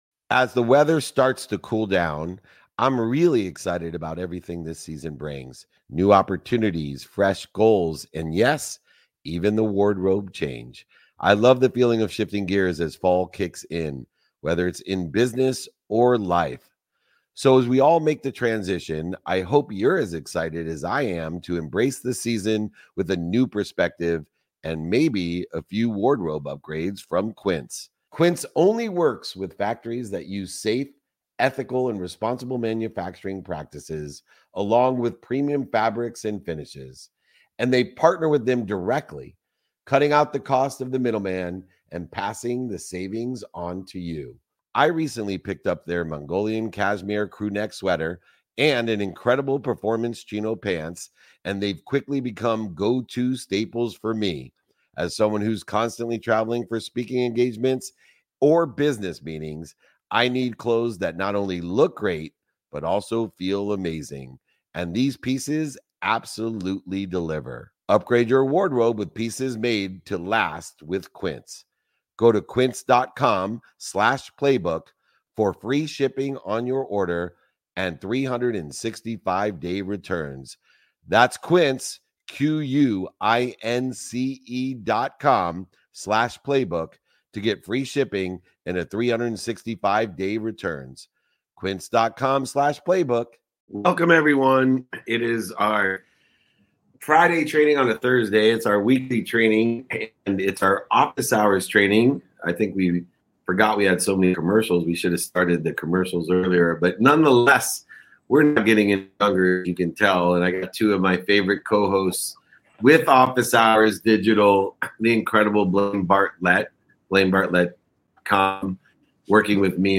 In this episode, I’m joined by a panel of experts to unpack how strategic thinking and technology can drive real impact in both business and healthcare.